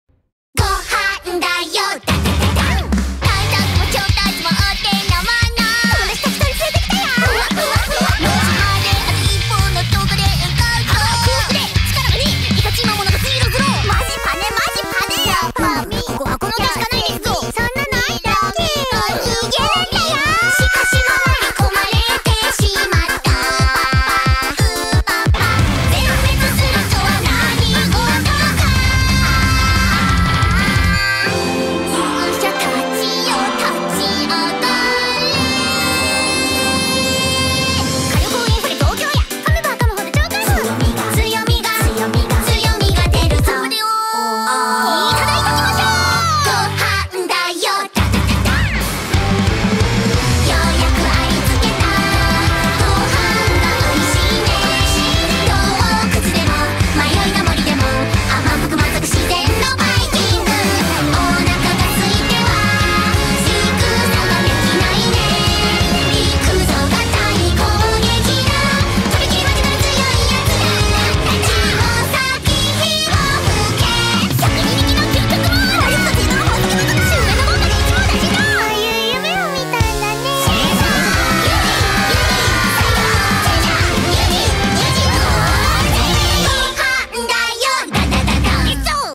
BPM45-180
Audio QualityPerfect (High Quality)